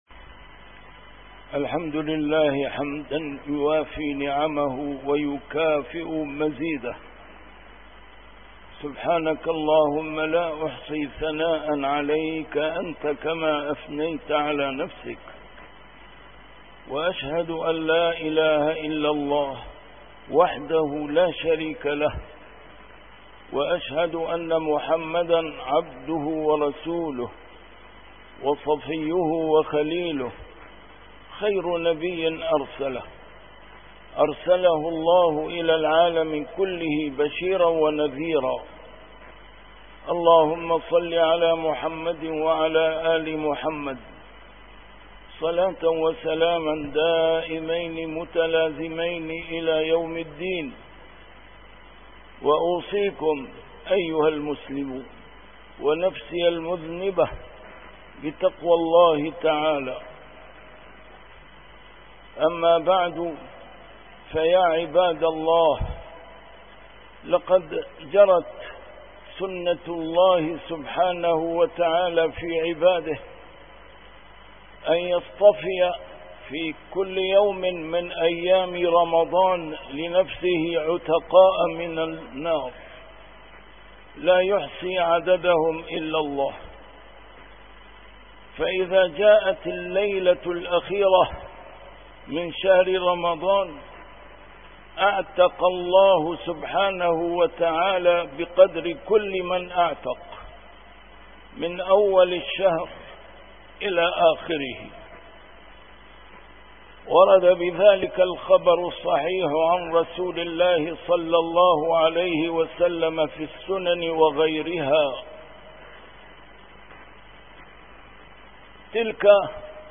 A MARTYR SCHOLAR: IMAM MUHAMMAD SAEED RAMADAN AL-BOUTI - الخطب - الهدية المخبأة والهدية الناجزة